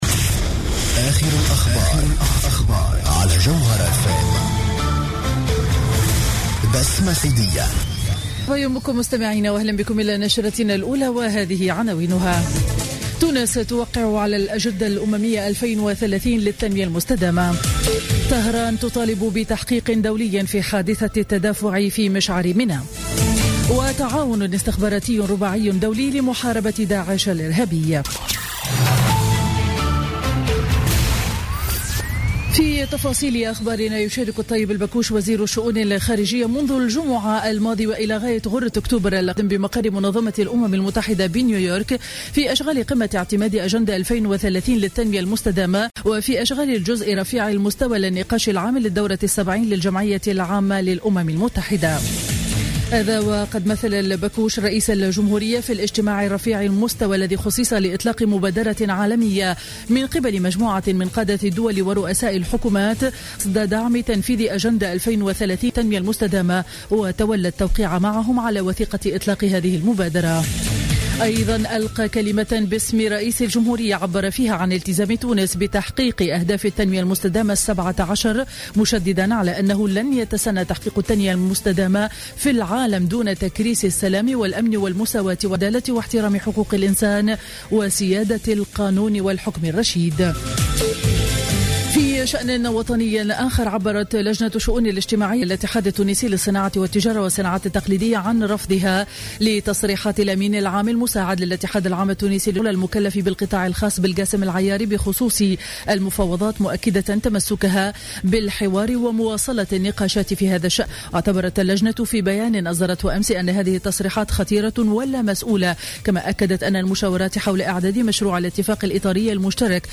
نشرة أخبار السابعة صباحا ليوم الأحد 27 سبتمبر 2015